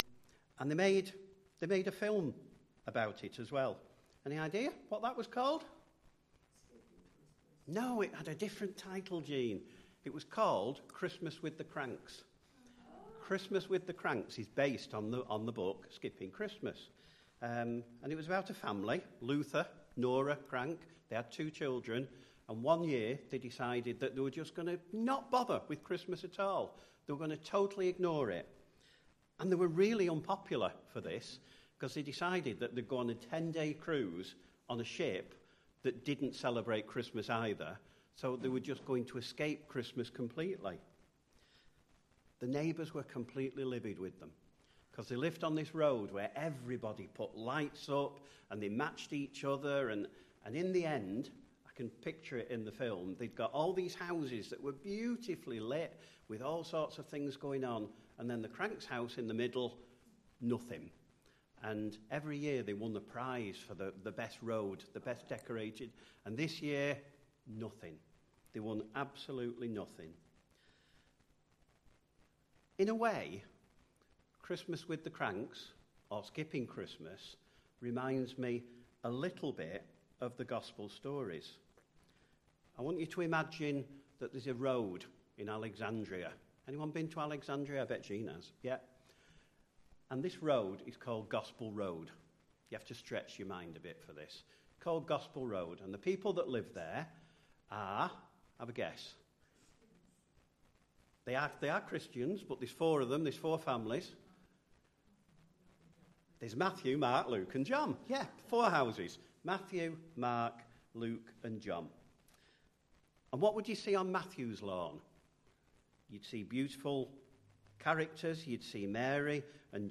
Sermons | Roman Road IM Church Failsworth
Christmas Day - Mark 1 v1-13.mp3